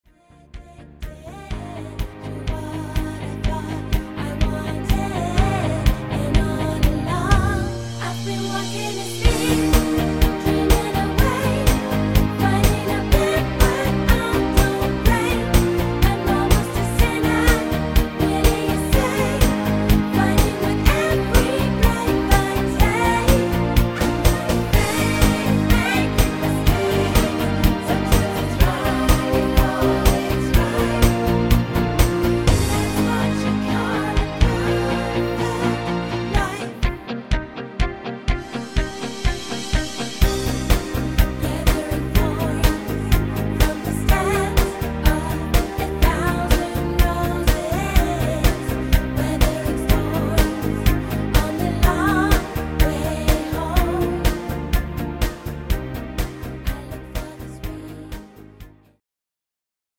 Orig. Tonart